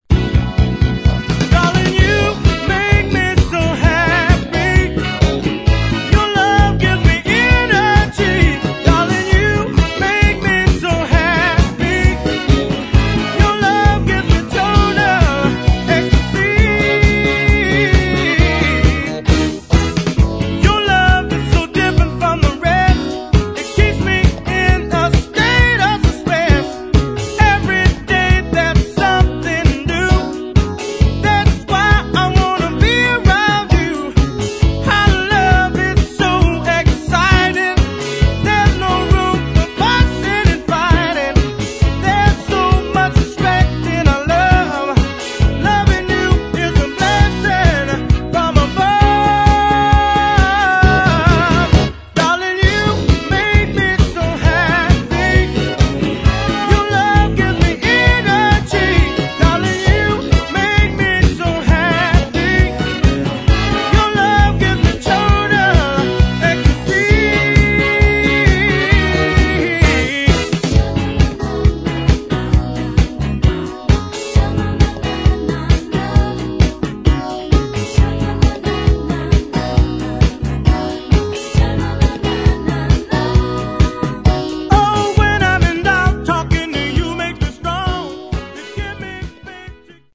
[ FUNK / SOUL / DISCO ]